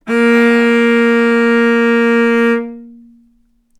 healing-soundscapes/Sound Banks/HSS_OP_Pack/Strings/cello/ord/vc-A#3-ff.AIF at 48f255e0b41e8171d9280be2389d1ef0a439d660
vc-A#3-ff.AIF